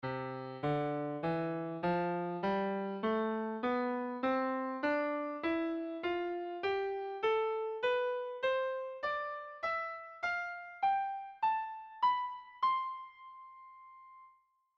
escala-diatonica1.mp3